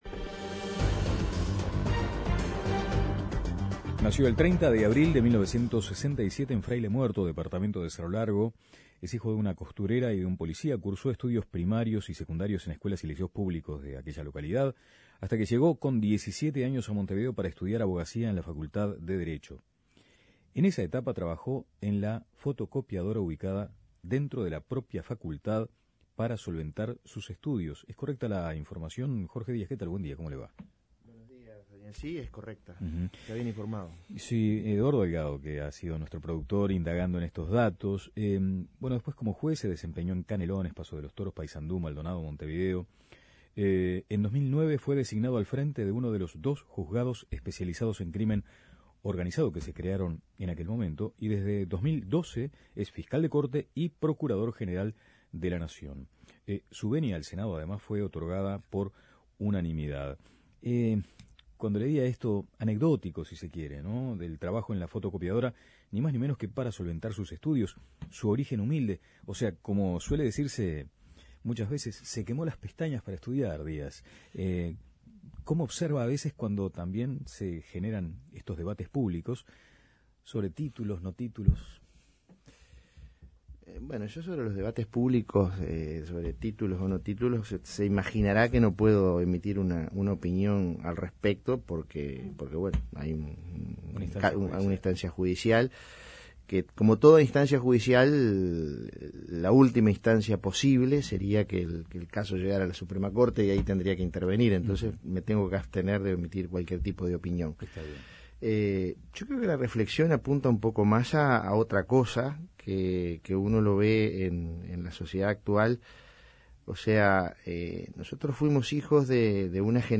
Entrevisado por La Mañana de El Espectador, Díaz se refirió a la situación presupuestal del Poder Judicial. En este sentido, negó que la Fiscalía General de la Nación esté en una situación de asfixia económica aunque reconoció que se solicitaron más recursos de los adjudicados.